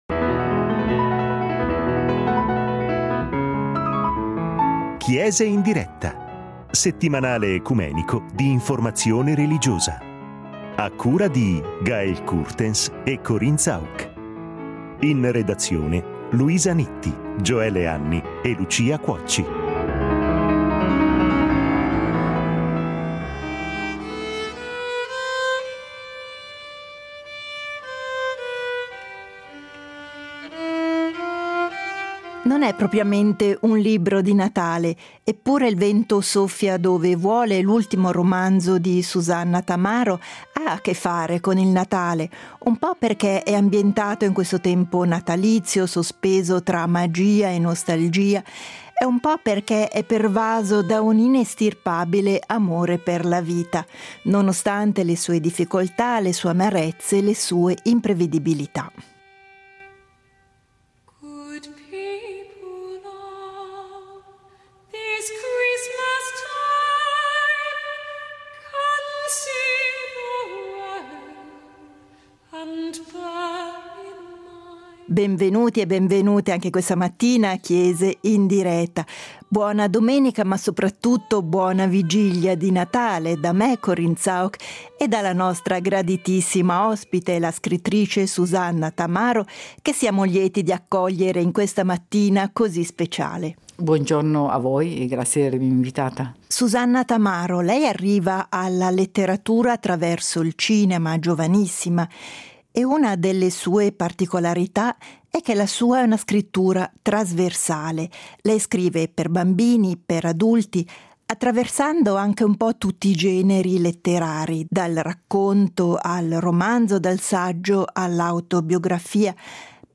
Ne parliamo con l’autrice del romanzo, Susanna Tamaro .